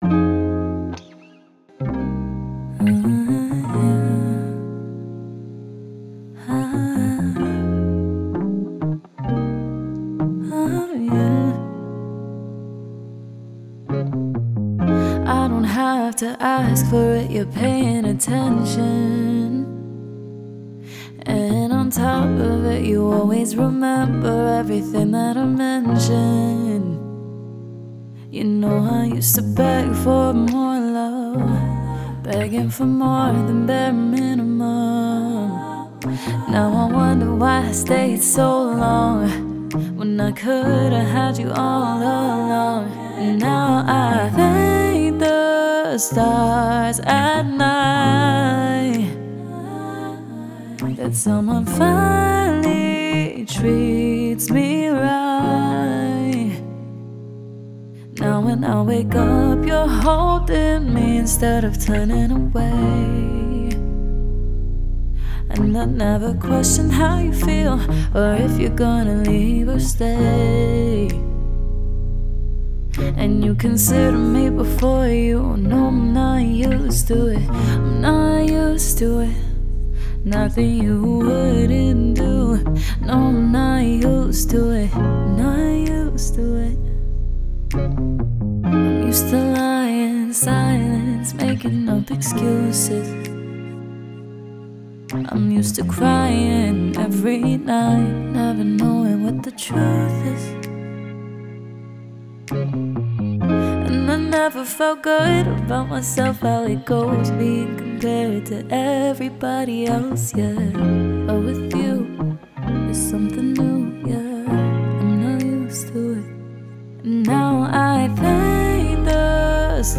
R&B, Pop
F Minor